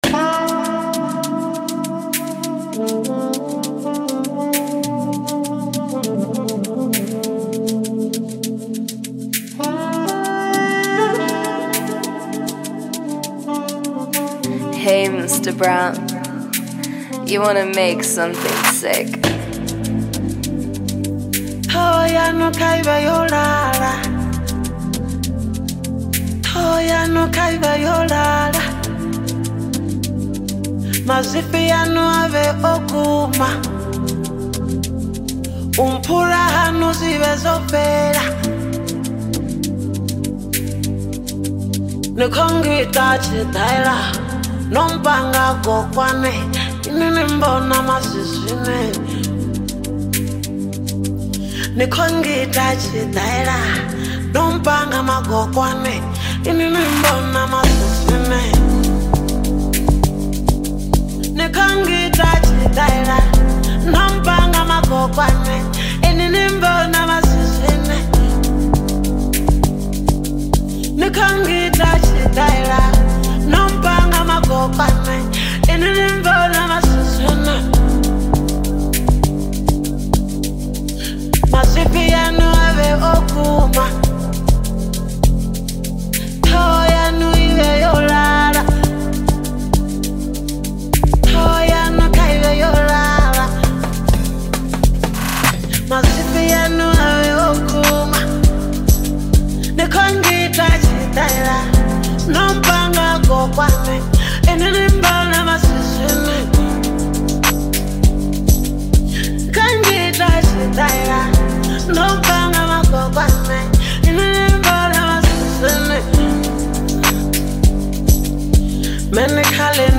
is a vibrant and high energy track
traditional African sounds and modern beats.